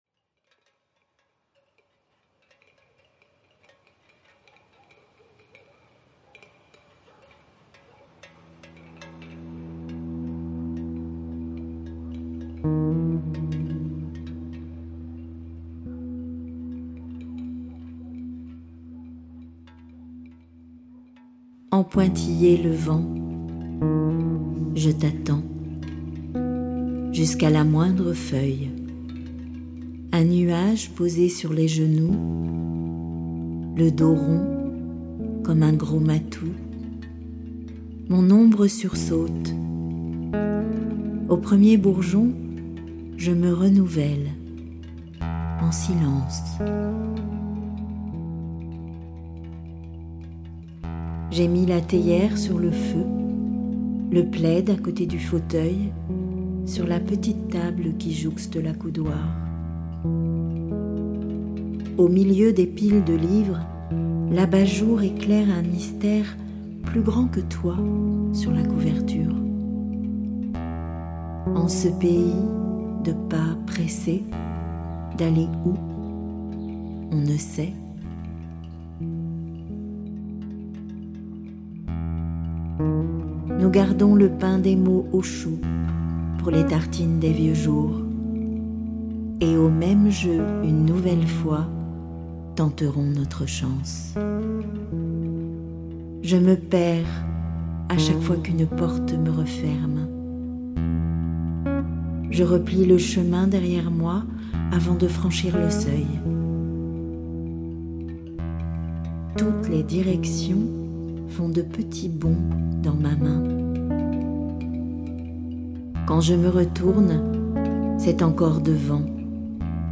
Home / Les livres , Livres sonores , Poèmes /
lecture en français
lecture en arabe